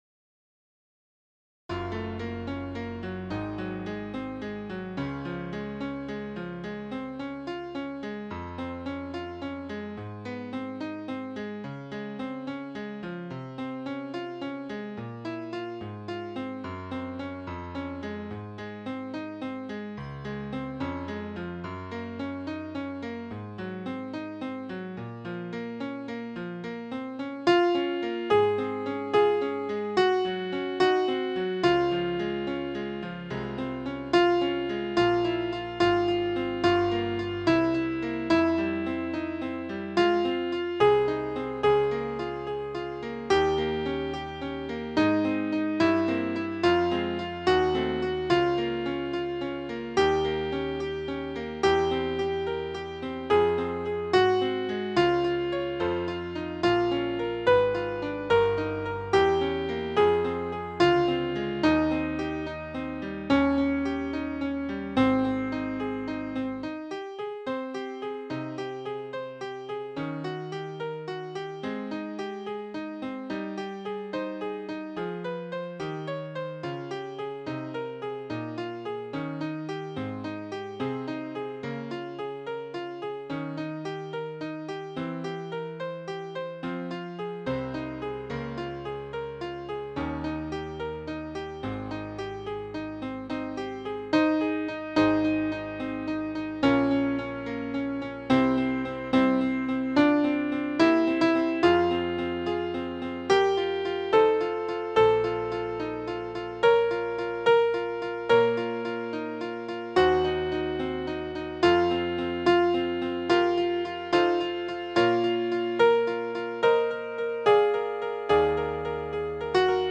Cantique de Jean Racine Alto.mp3